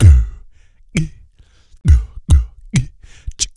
BASS BOMB.wav